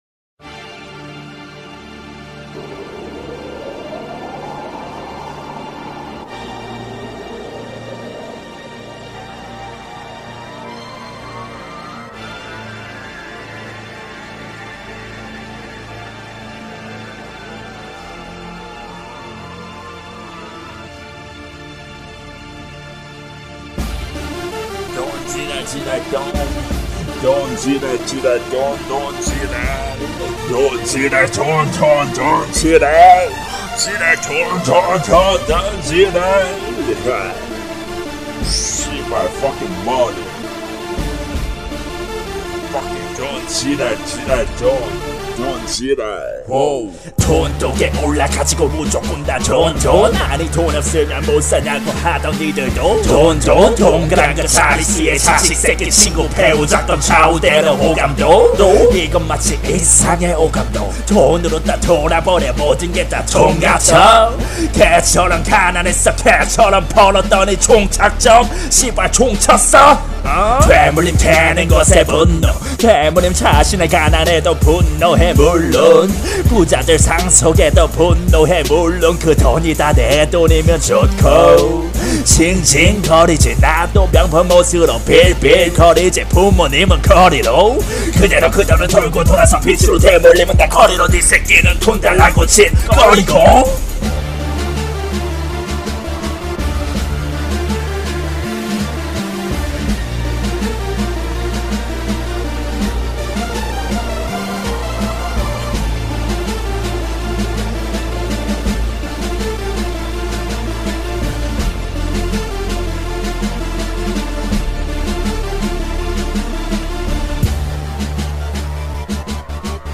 • [REMIX.]